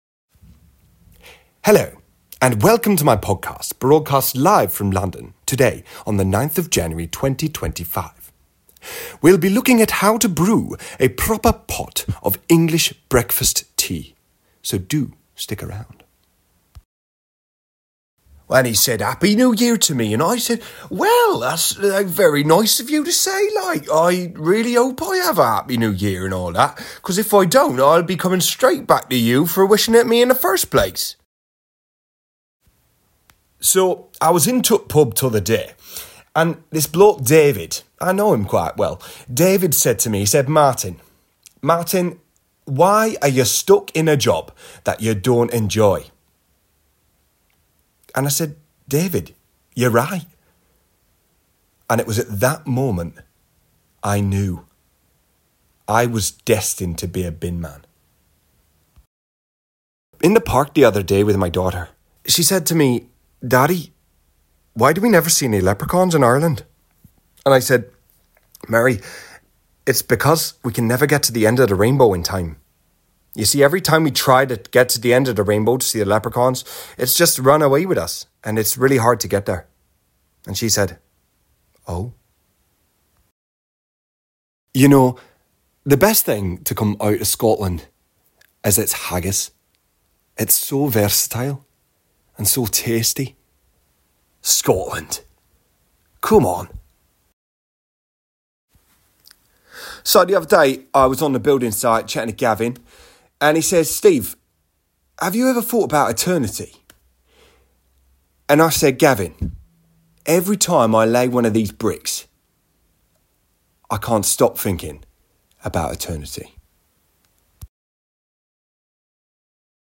Velvety, Deep, Charming, Upbeat, Relatable, Smooth